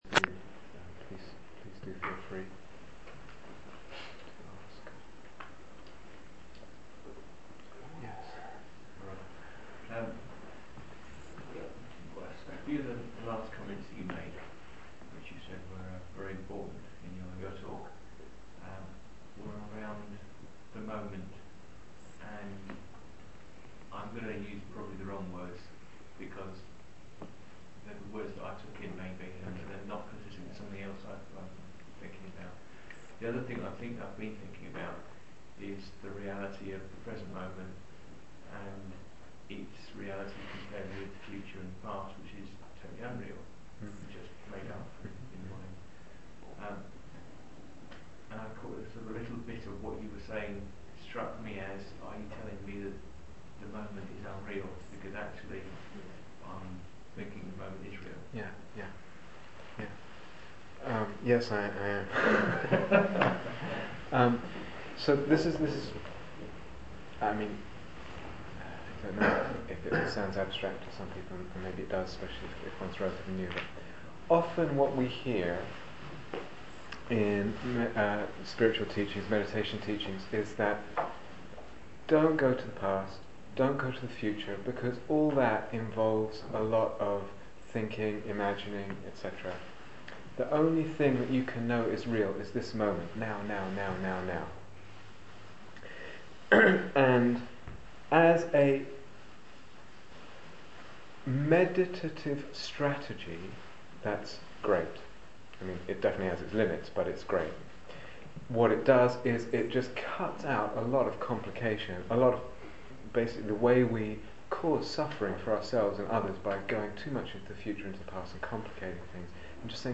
Questions and Answers
Questions and Answers Download 0:00:00 --:-- Date 1st April 2008 Retreat/Series Cambridge Day Retreats 2008 Transcription Please do feel free to ask.